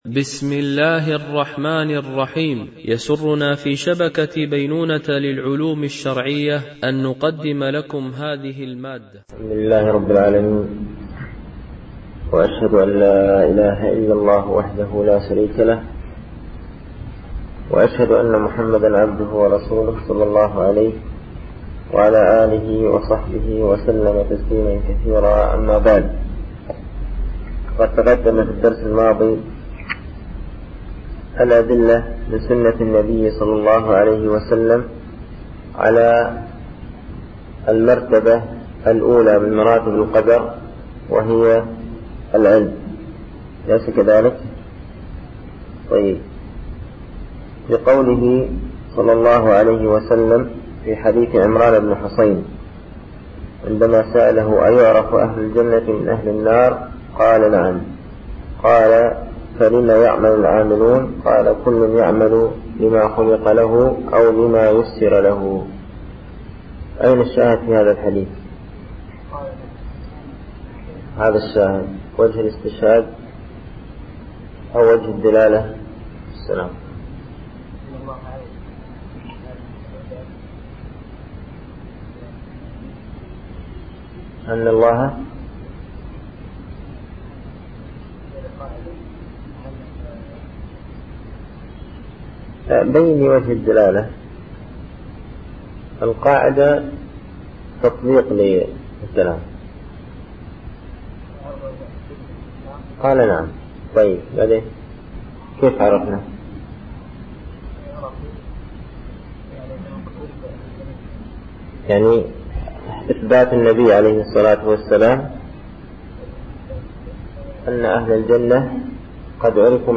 ) الألبوم: شبكة بينونة للعلوم الشرعية التتبع: 59 المدة: 35:57 دقائق (8.25 م.بايت) التنسيق: MP3 Mono 22kHz 32Kbps (CBR)